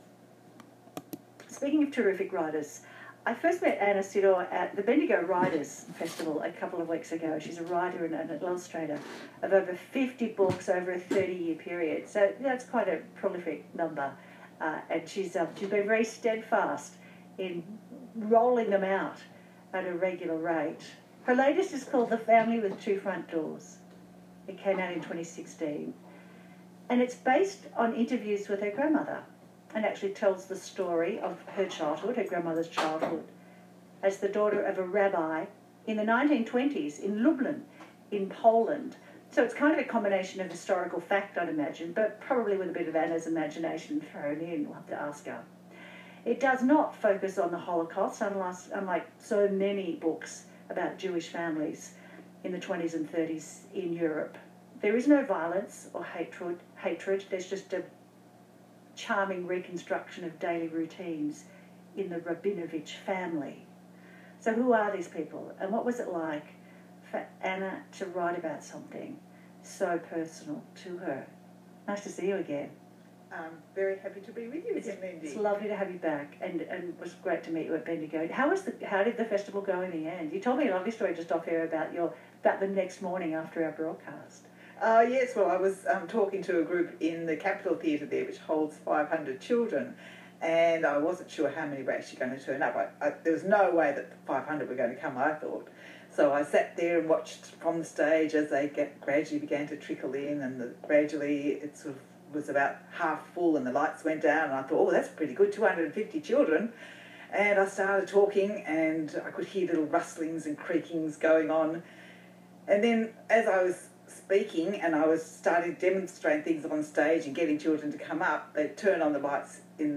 Interviews from TV, radio, podcasts, blogs re author secrets, research stories